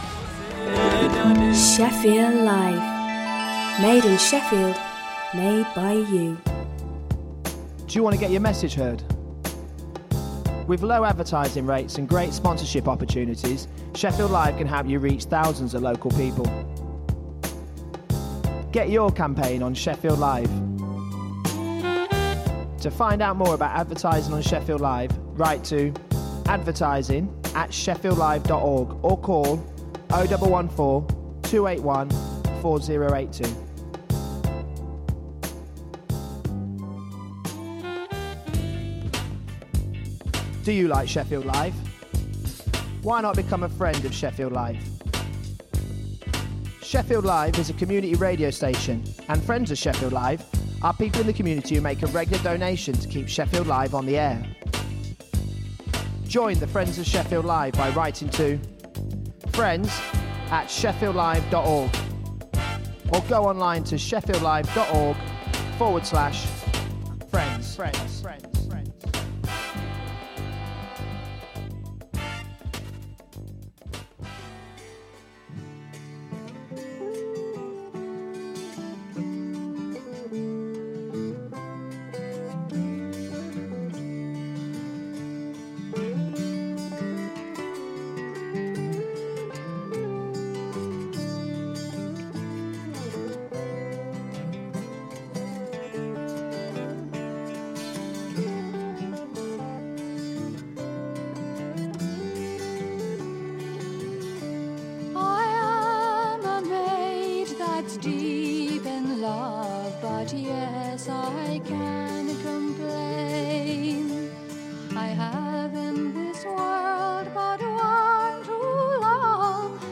Magazine programme for the Chinese community.